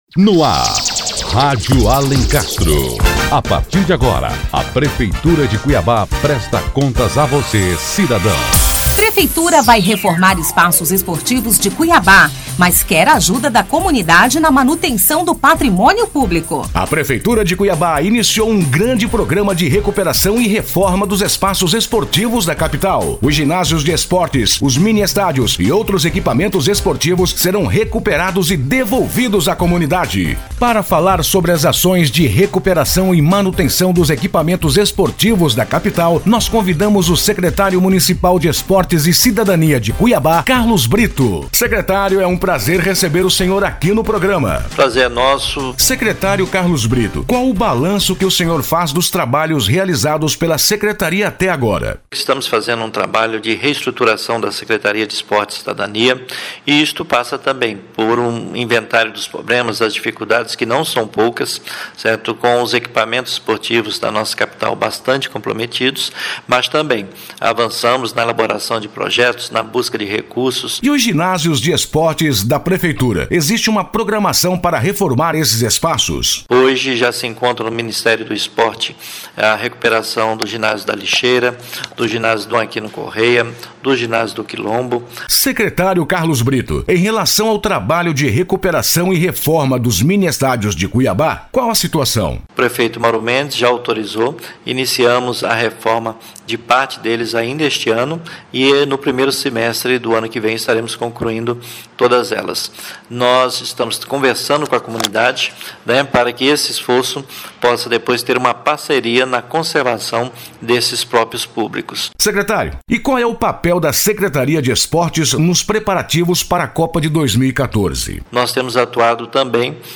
O Secretário Municipal de Esportes, Cidadania e Juventude, Carlos Brito, é entrevistado sobre as ações de...